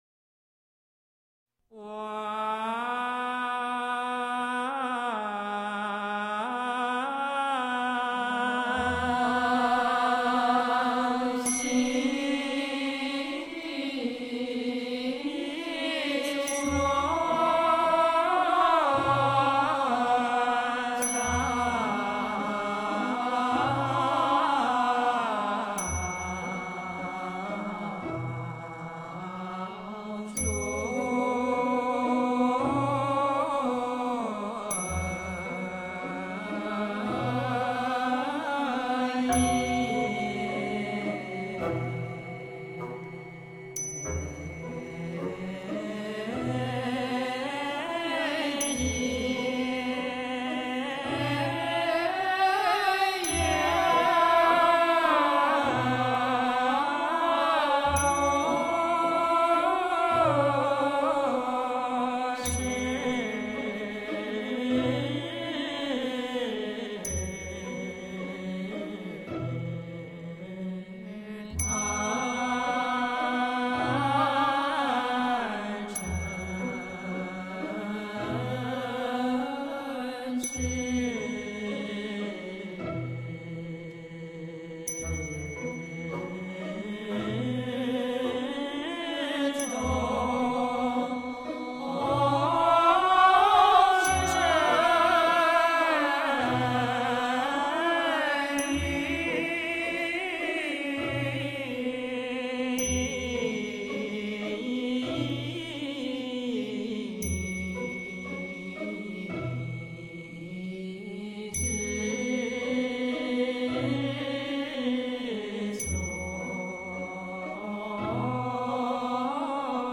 佛音 诵经 佛教音乐 返回列表 上一篇： 四十八愿度众生 下一篇： 四月八 相关文章 醒来(童音版